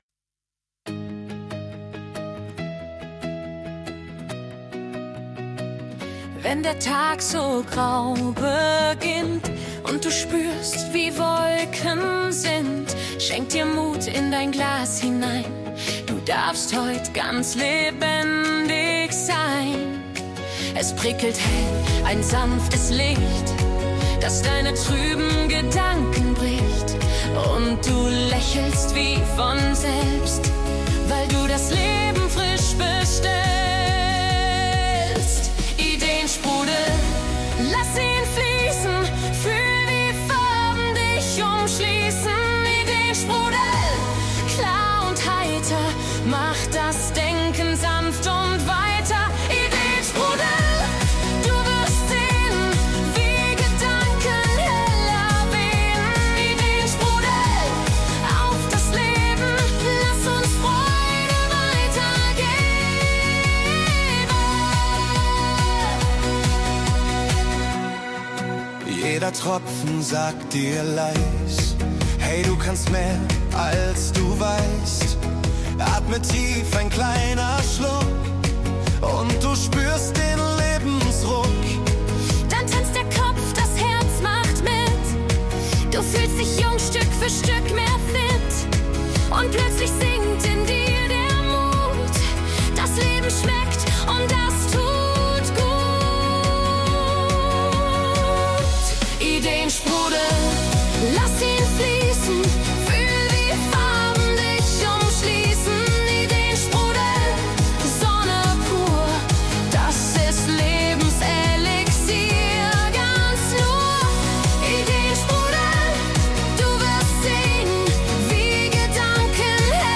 147 Ideensprudel - Ihr Song im Schlagermove für messbar mehr Kreativität